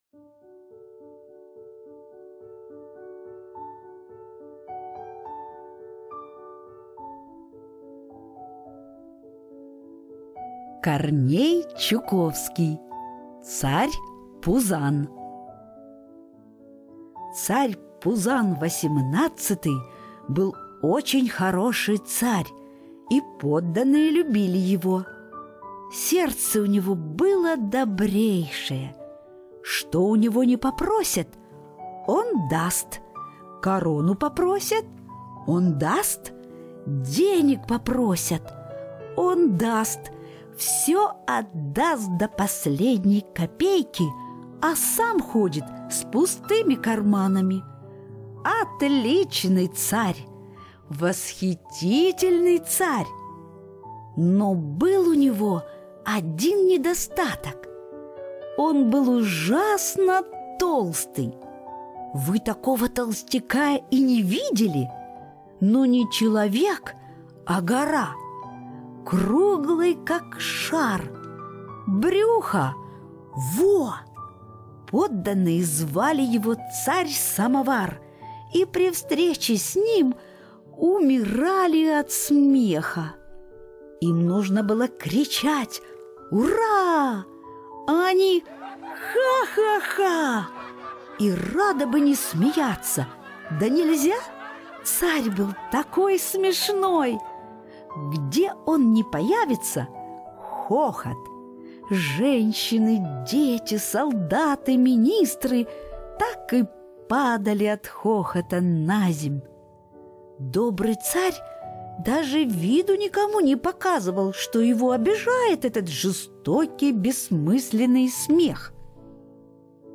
Царь Пузан - аудиосказка Корнея Чуковского - слушать онлайн